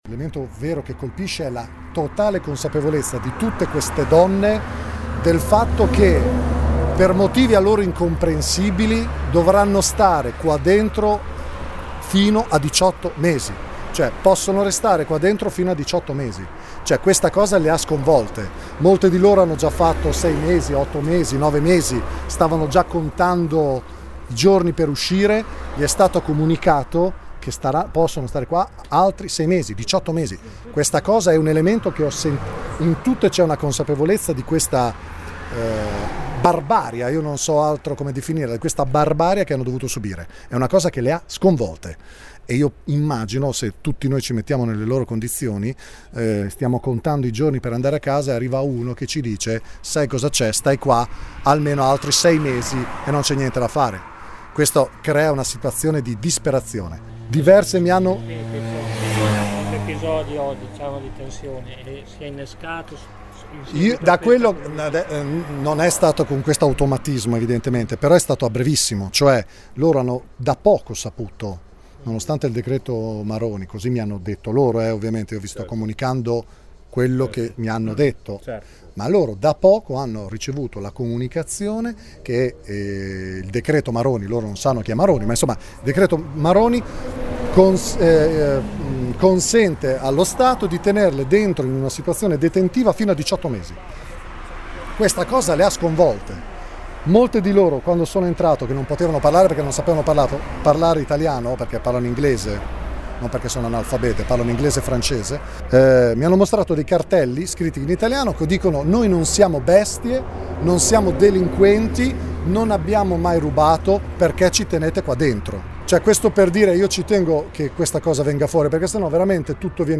Ascolta Sconciaforni all’uscita dal Cie